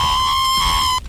Play, download and share EEEEEEEEeeeeeee original sound button!!!!
eeeeee_3.mp3